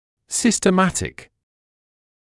[ˌsɪstə’mætɪk][ˌсистэ’мэтик]систематический; системный